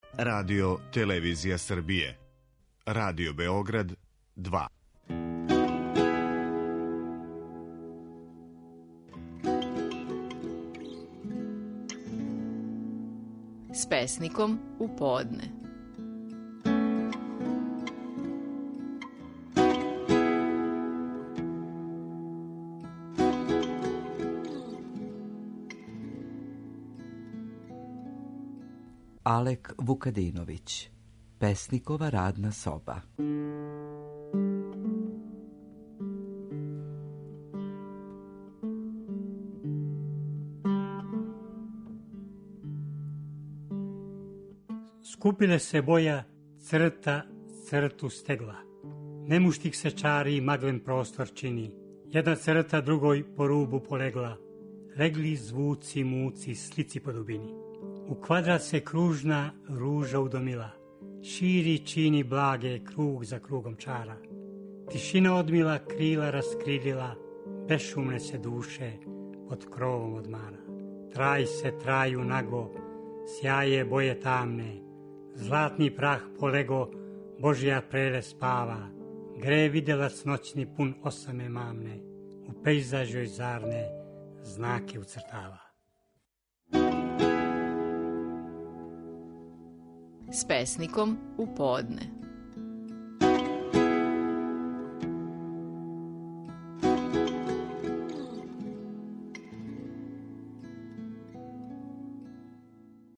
Стихови наших најпознатијих песника, у интерпретацији аутора.
Алек Вукадиновић говори своју песму „Песникова радна соба".